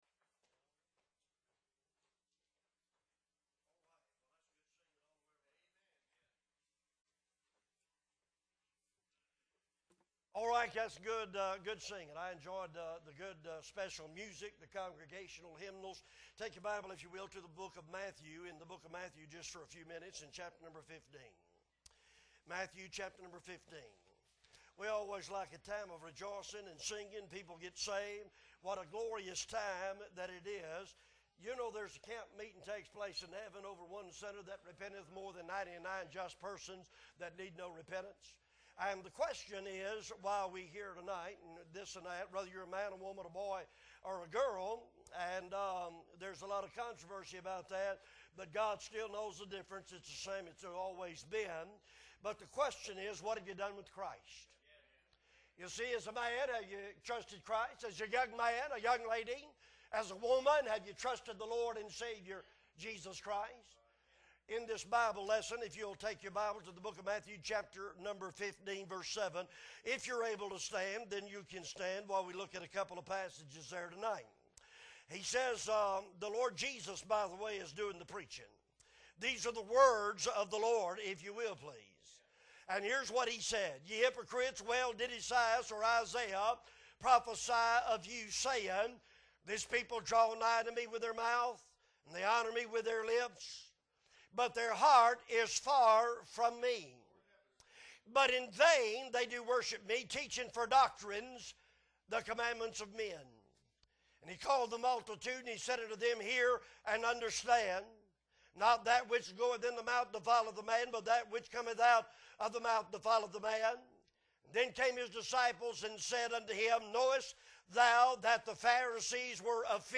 August 28, 2022 Sunday Evening Service - Appleby Baptist Church